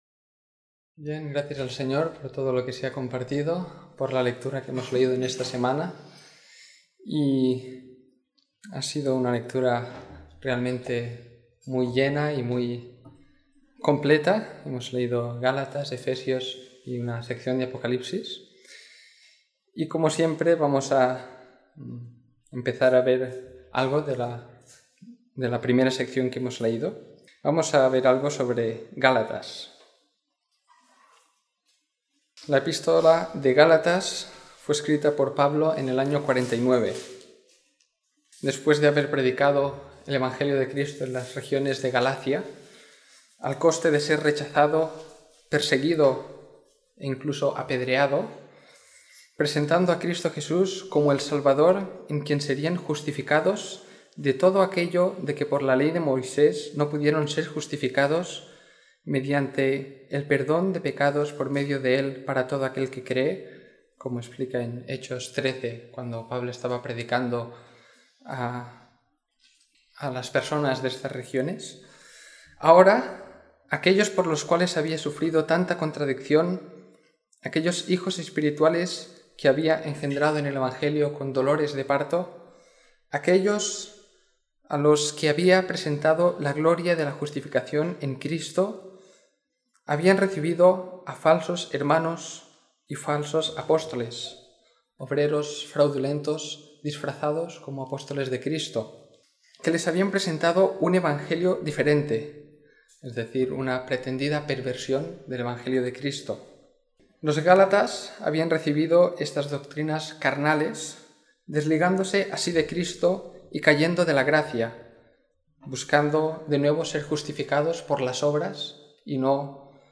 Comentario en la epístola de Gálatas siguiendo la lectura programada para cada semana del año que tenemos en la congregación en Sant Pere de Ribes.